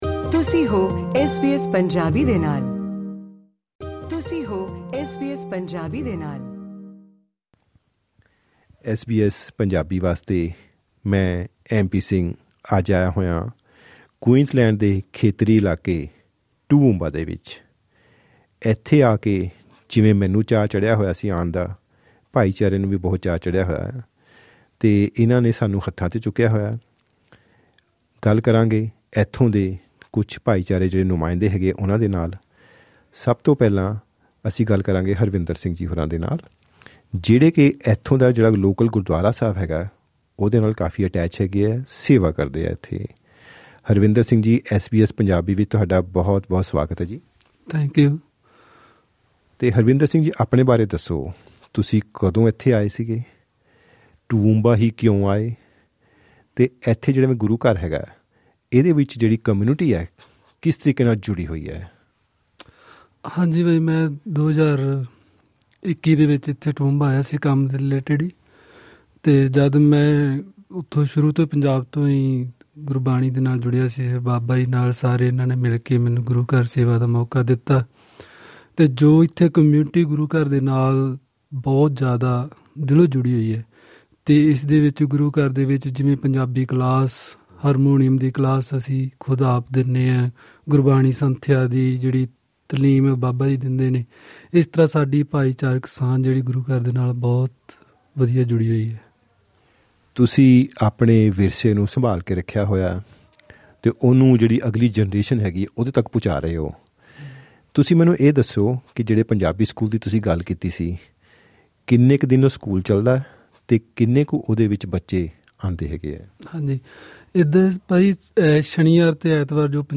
ਇਸ ਖਾਸ ਮੌਕੇ ਬੁੱਧਵਾਰ, 18 ਜੂਨ ਵਾਲੇ ਦਿਨ ਐਸ ਬੀ ਐਸ ਨੇ ਕੂਈਨਜ਼ਲੈਂਡ ਦੇ ਖੇਤਰੀ ਇਲਾਕੇ ਟੂਵੂਮਬਾ ਵਿੱਚ ਪਹੁੰਚ ਕੇ ਆਪਣੇ ਪੰਜਾਬੀ ਪ੍ਰੋਗਰਾਮ ਦਾ ਲਾਈਵ ਪ੍ਰਸਾਰਣ ਕੀਤਾ। ਇਸ ਦੌਰਾਨ ਸਮਾਜਿਕ ਏਕਤਾ ਦੇ ਮੁੱਦਿਆਂ 'ਤੇ ਚਰਚਾ ਦੇ ਨਾਲ-ਨਾਲ ਇਸ ਖੇਤਰ ਵਿੱਚ ਵਸਣ ਦੀ ਉਨ੍ਹਾਂ ਦੀ ਯਾਤਰਾ ਬਾਰੇ ਗੱਲਾਂ-ਬਾਤਾਂ ਅਤੇ ਖੇਤਰੀ ਇਲਾਕਿਆਂ ਵਿੱਚ ਰਹਿਣ ਦੀਆਂ ਚੁਣੌਤੀਆਂ ਤੇ ਉੱਥੇ ਉਪਲਬਧ ਸ਼ਾਨਦਾਰ ਮੌਕਿਆਂ ਬਾਰੇ ਵੀ ਵਿਚਾਰ-ਵਟਾਂਦਰੇ ਹੋਏ।